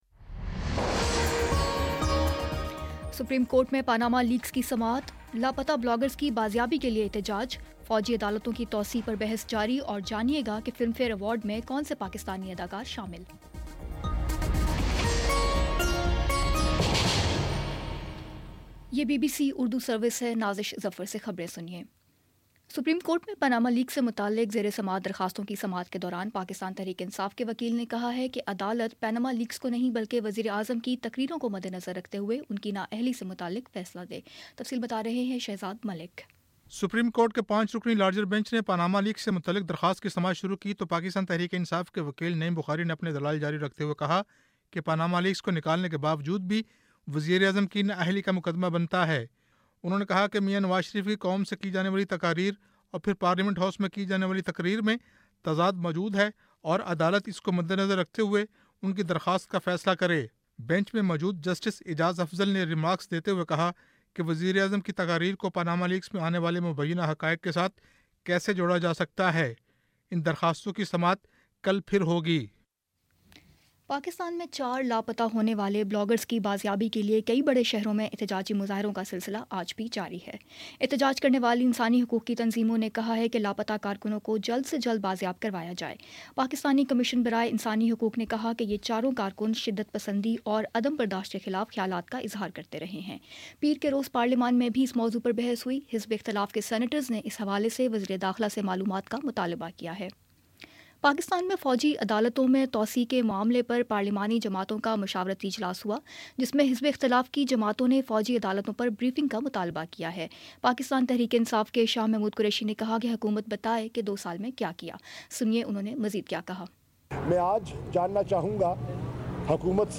جنوری 10 : شام پانچ بجے کا نیوز بُلیٹن